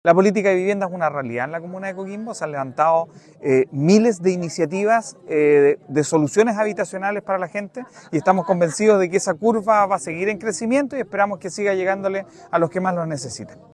Ali Manouchehri – Alcalde de Coquimbo
Ali-Manouchehri-Alcalde-de-Coquimbo.mp3